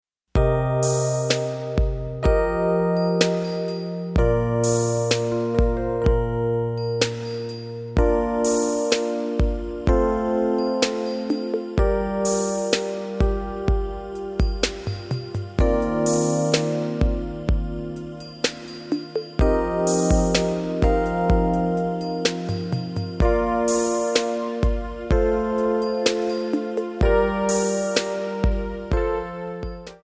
Backing track files: Pop (6706)